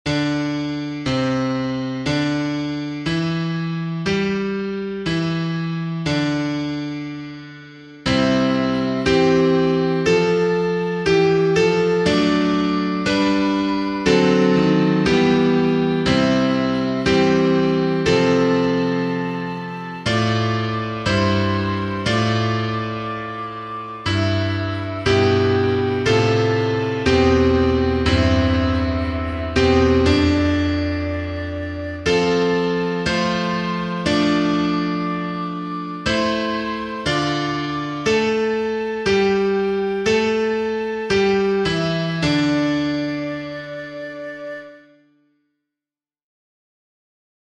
National_Anthem_of_Banana_Kingdom-Accompaniment_only-1.mp3